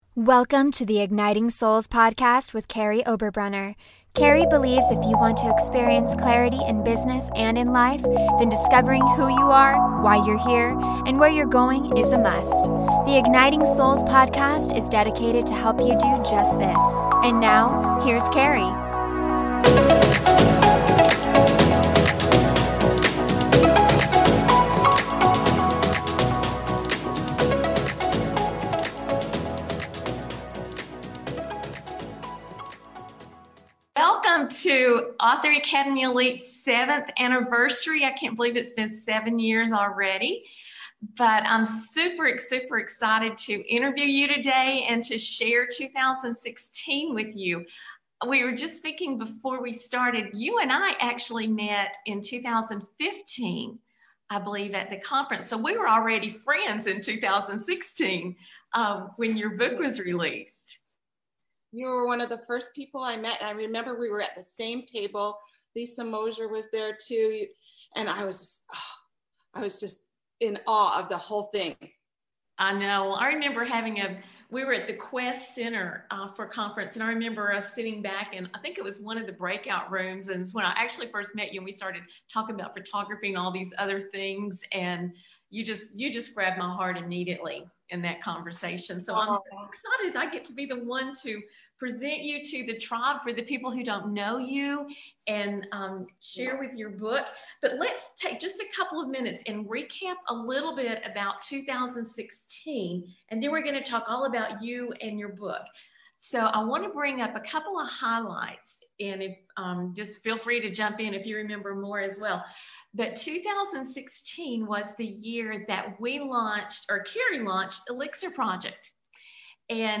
This interview is part of our 7-year celebration of Author Academy Elite.